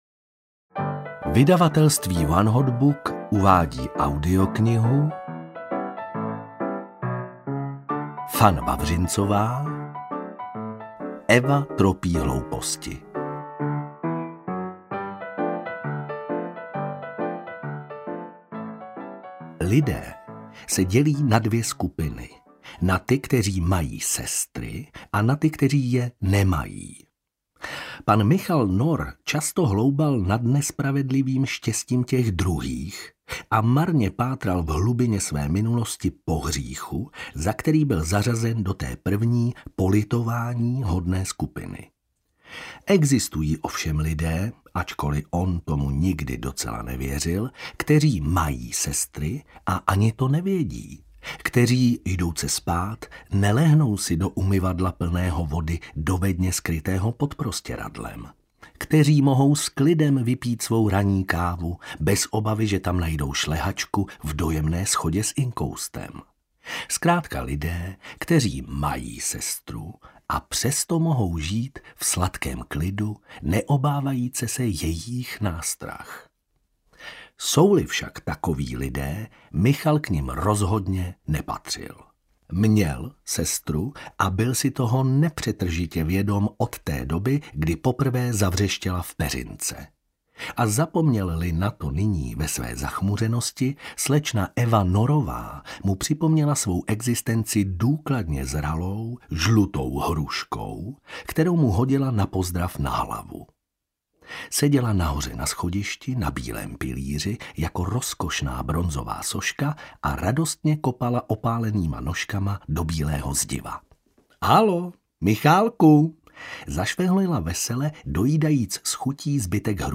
Eva tropí hlouposti audiokniha
Ukázka z knihy